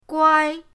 guai1.mp3